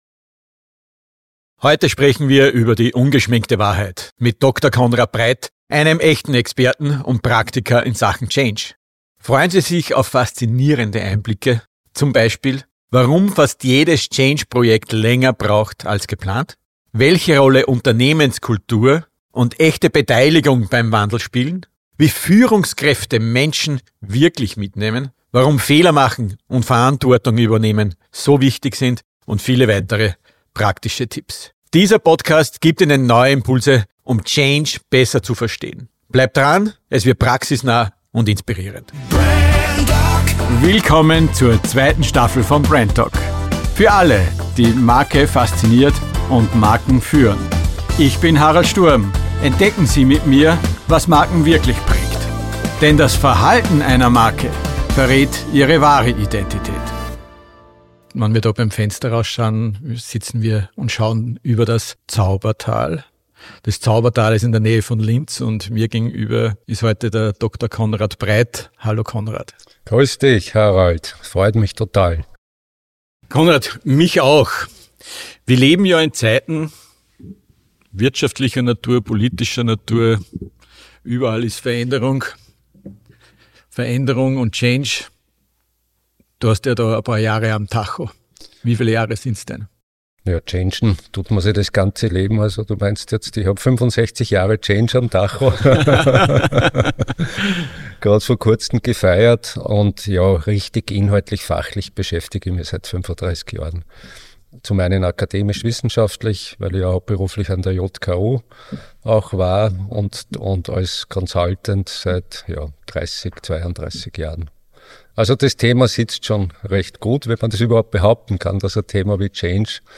Direkt aus dem Zaubertal bei Linz geben die beiden wertvolle Einblicke aus über 35 Jahren Berufserfahrung und Leadership.